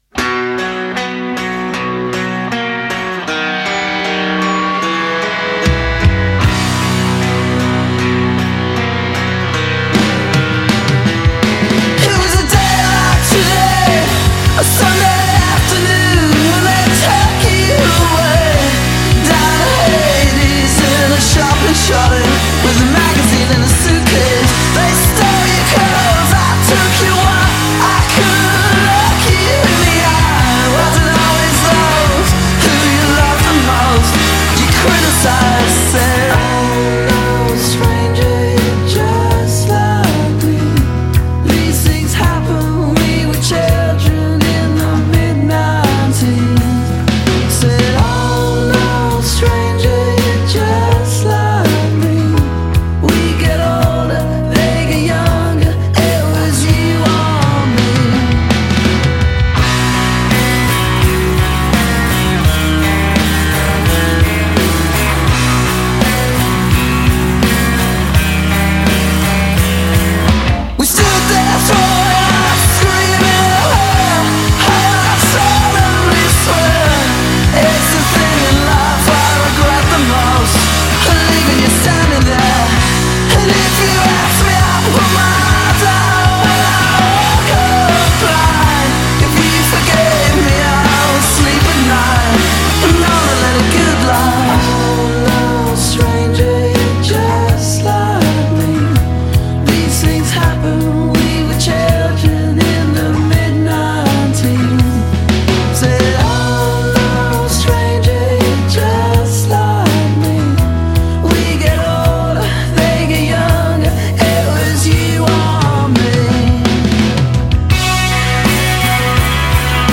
anthemic chorus